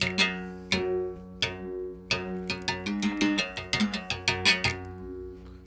32 Berimbau 01.wav